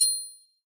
DDW4 PERC 7.wav